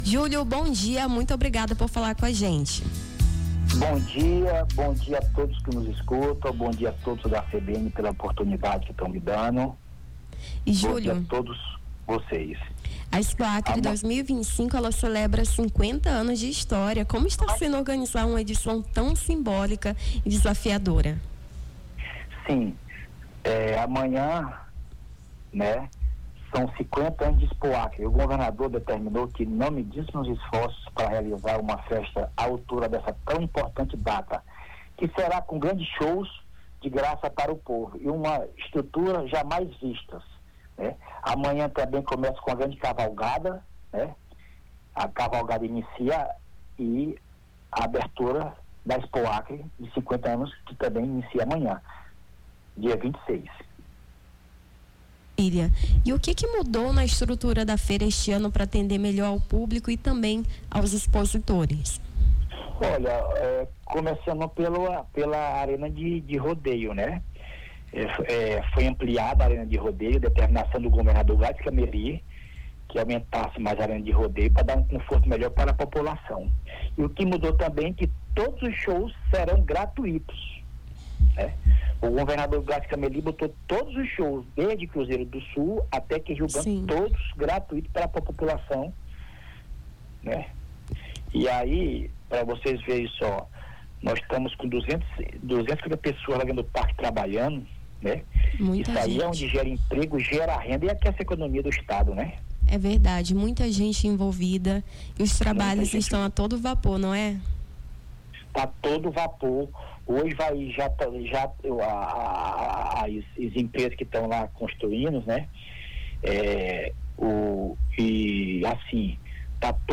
Rio Branco